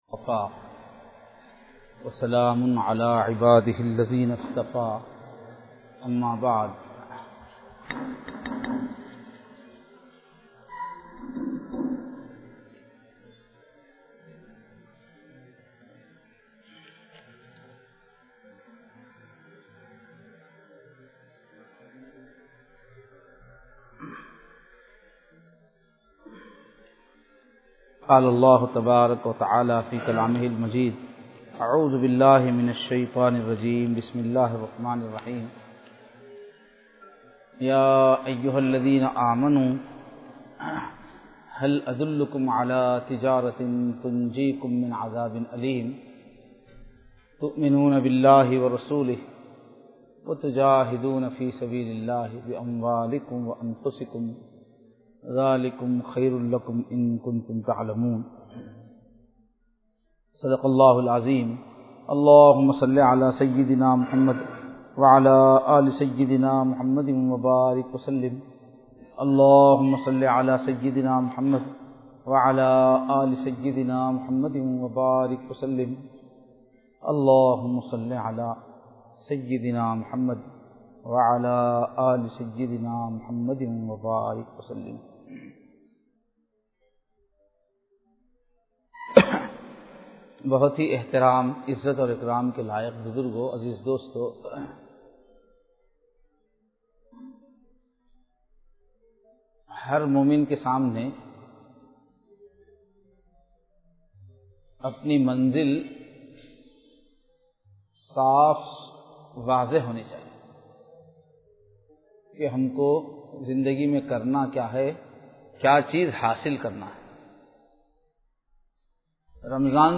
Bayanath